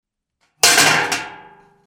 Звуки ведра
Стук по ведру